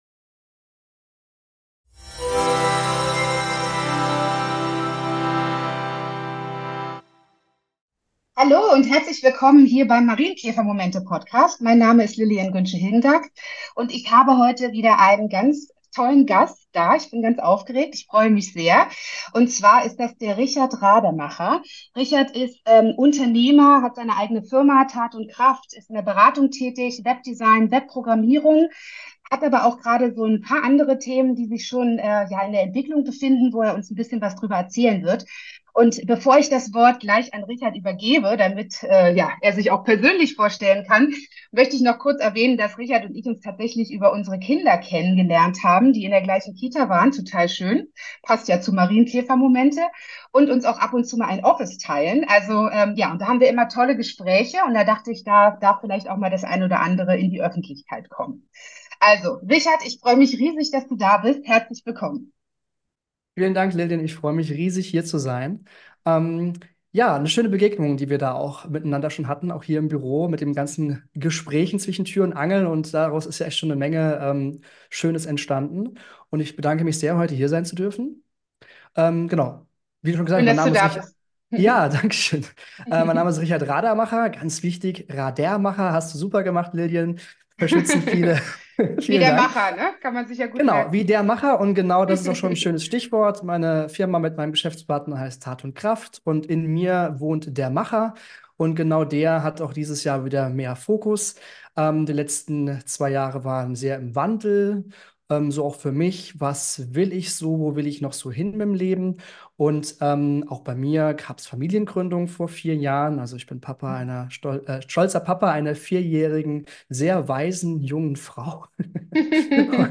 ein Interview-Gespräch